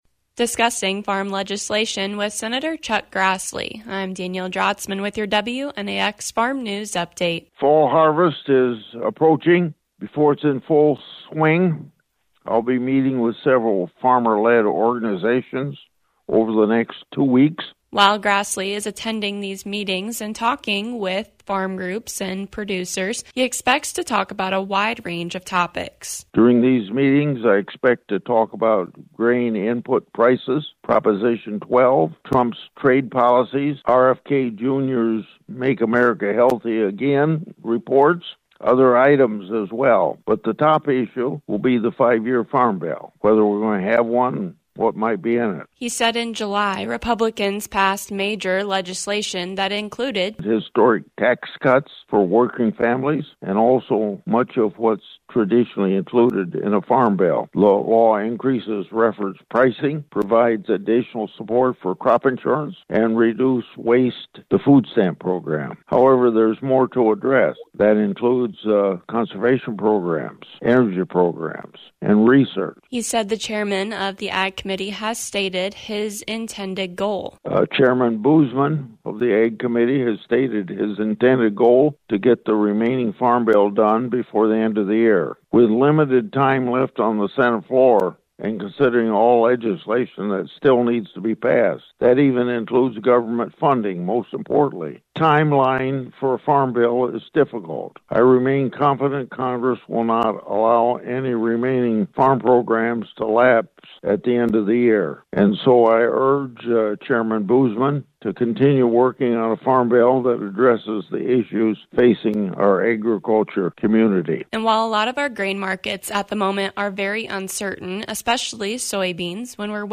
Senator Chuck Grassley discusses recent farm legislation.